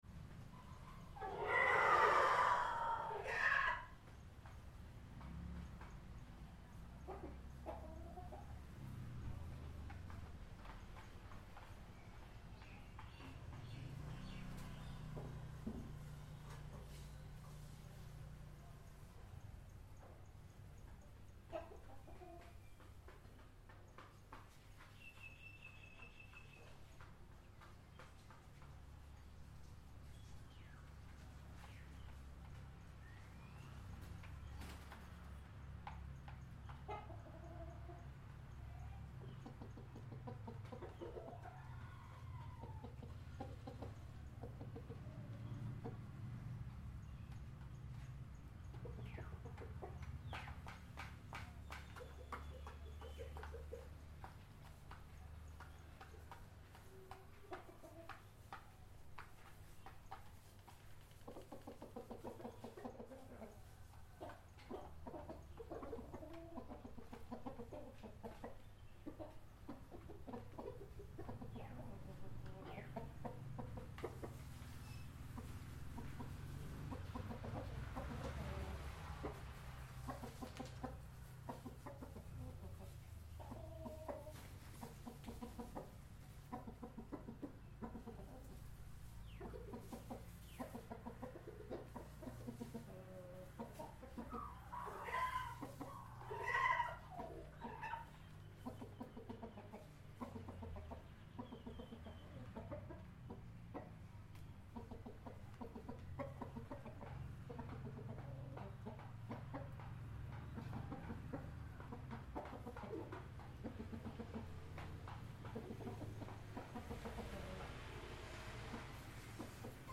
دانلود آهنگ مرغ و خروس در لانه از افکت صوتی انسان و موجودات زنده
دانلود صدای مرغ و خروس در لانه از ساعد نیوز با لینک مستقیم و کیفیت بالا
جلوه های صوتی